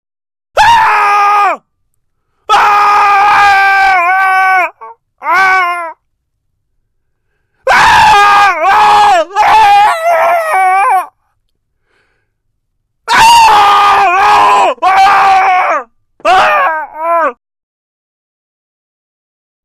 6 Нечеловеческие крики MP3 / 308 Кб [
06-inhuman_screams.mp3